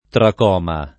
tracoma [ trak 0 ma ] s. m. (med.); pl. ‑mi